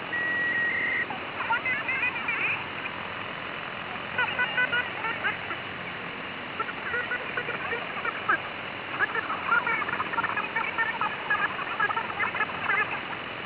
Запись сигнала